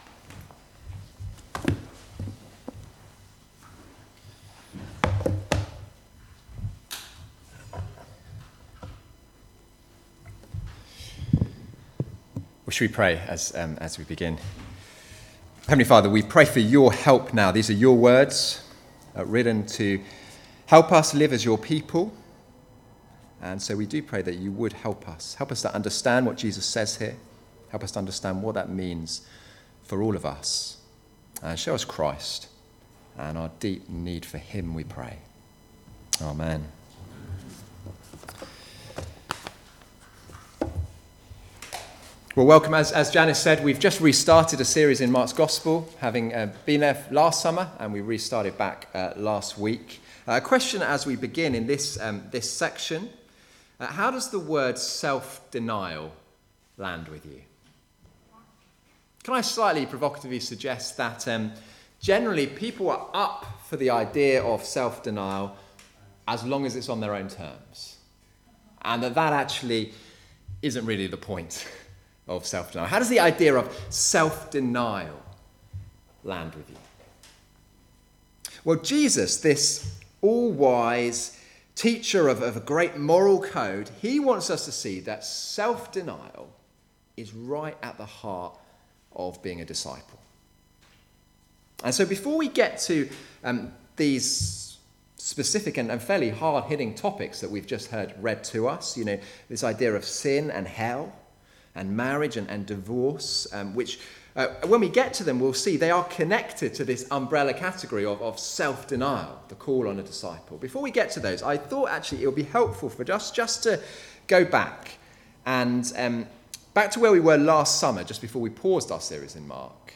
Passage: Mark 9:42-10:16 Service Type: Weekly Service at 4pm